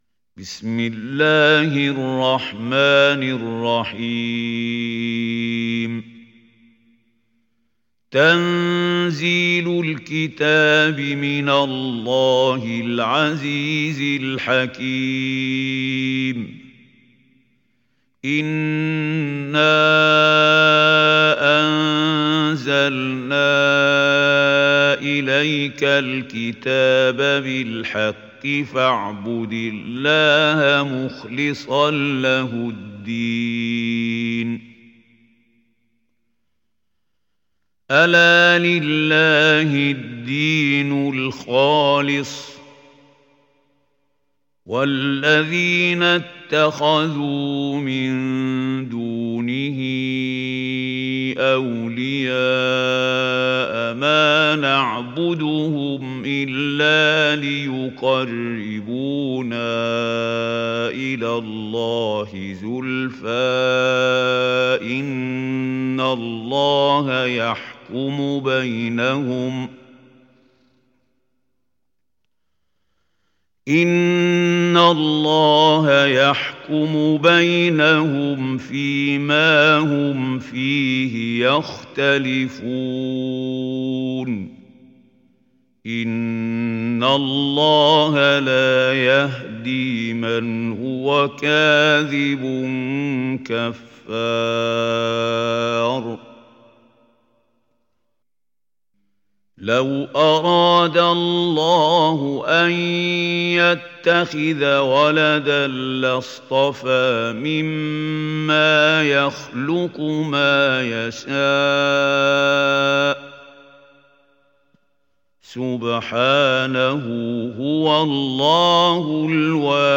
Surat Az zumar mp3 Download Mahmoud Khalil Al Hussary (Riwayat Hafs)